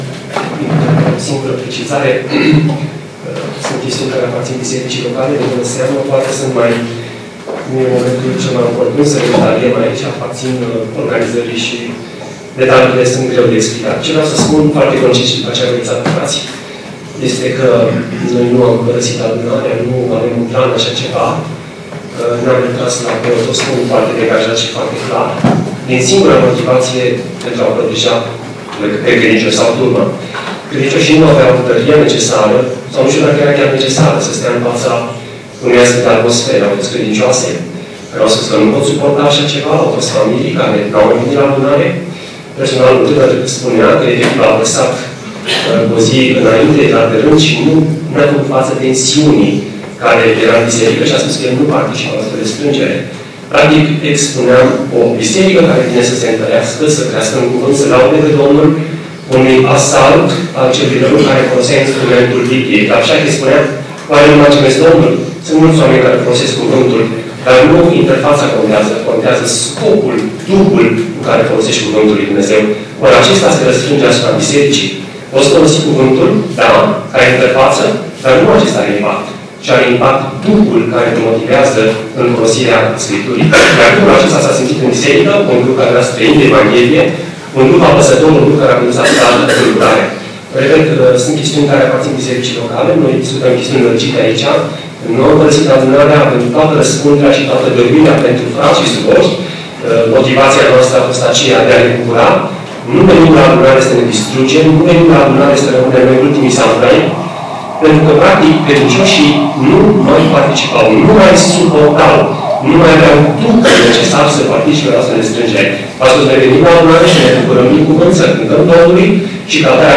Conferință, după-amiaza
Predică